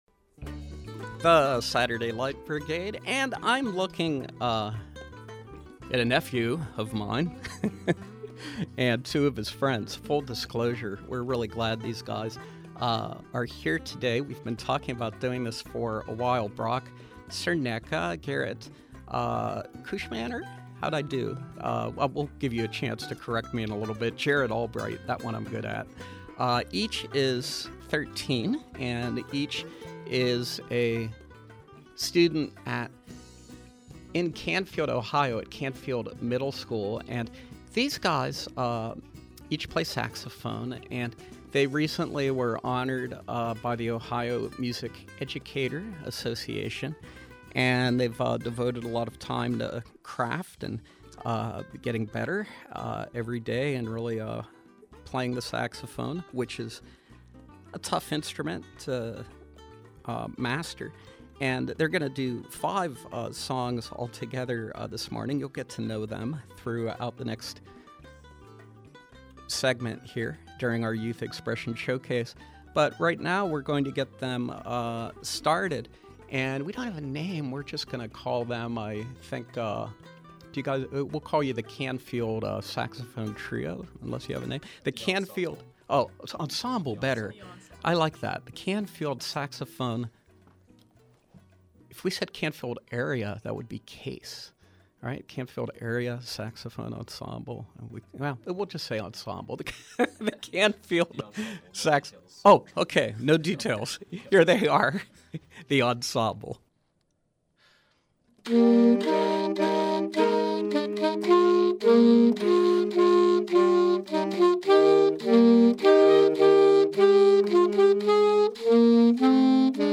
The saxophone trio
playing live in our studios